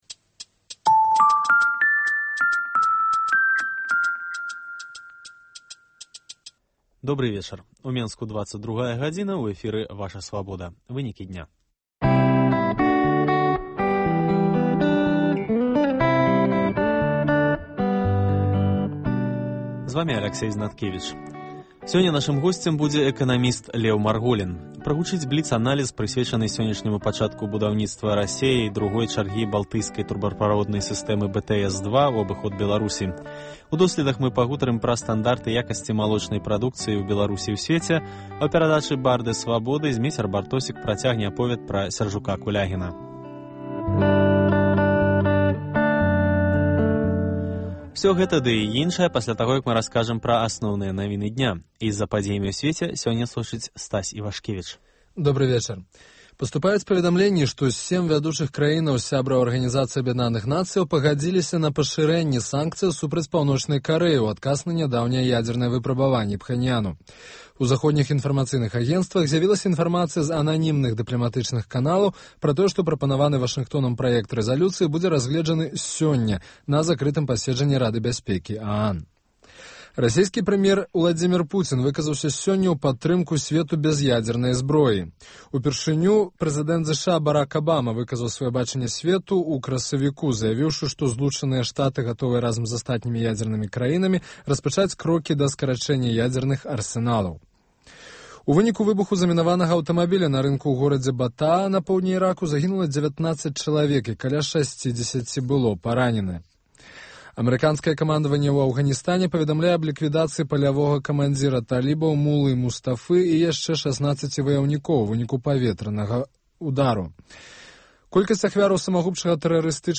Асноўныя падзеі, бліц-аналіз, досьледы і конкурсы, жывыя гутаркі, камэнтары слухачоў, прагноз надвор'я, "Барды Свабоды".